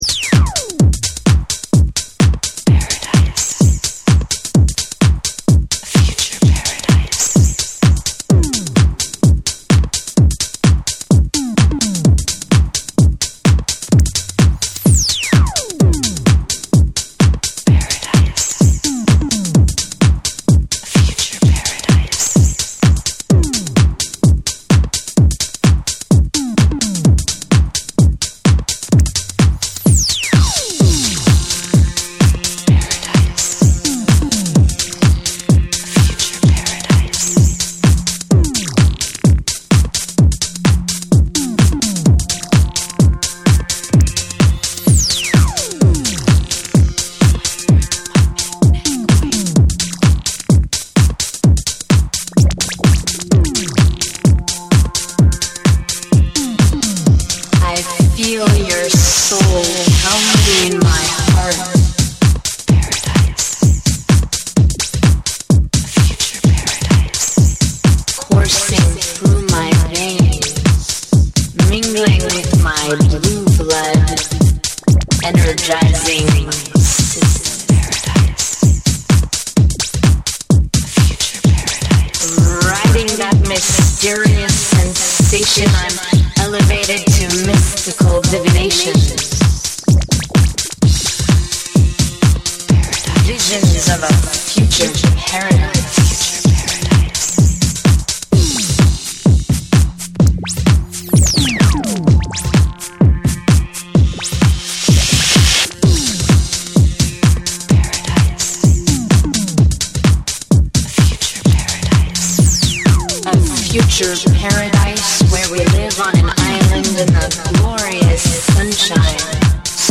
多幸感あふれるシンセのメロディーとヴォーカル・サンプル、そして柔らかくうねるベースラインでフロアを包み込む
TECHNO & HOUSE